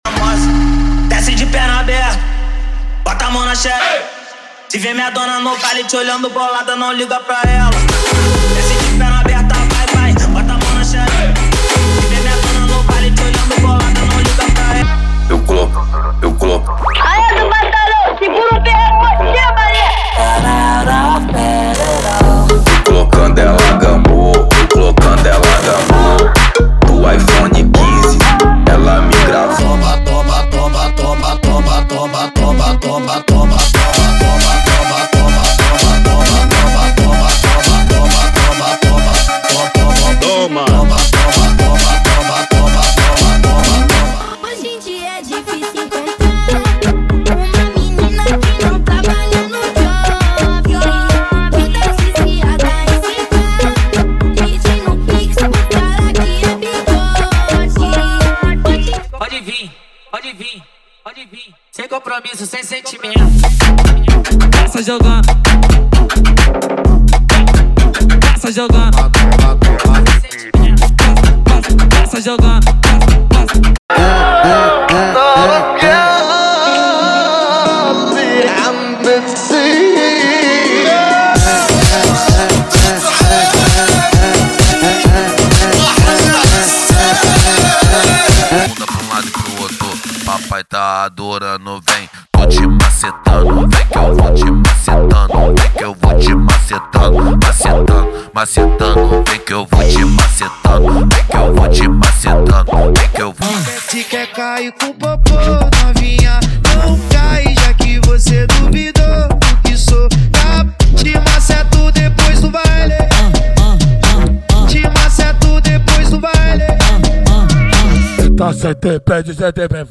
• Eletro Funk = 50 Músicas
• Sem Vinhetas
• Em Alta Qualidade